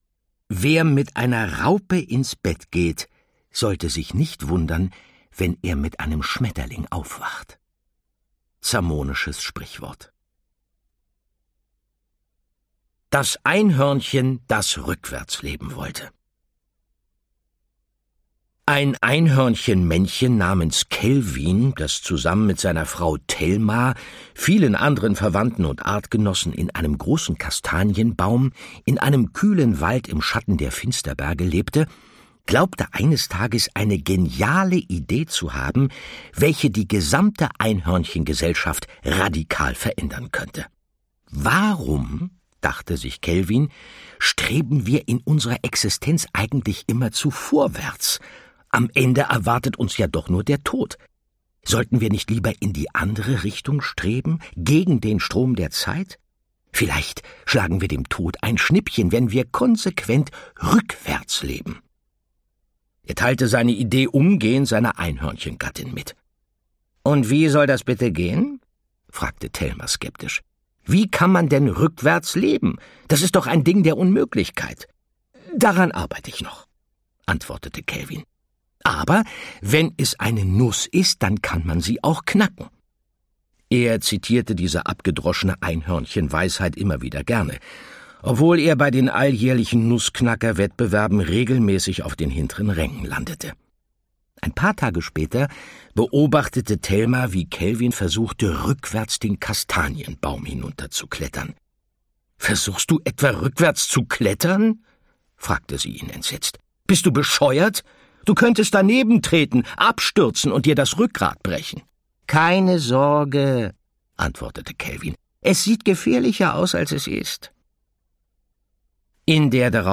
Andreas Fröhlich (Sprecher)
Ungekürzte Lesung